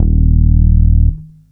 5-D#1.wav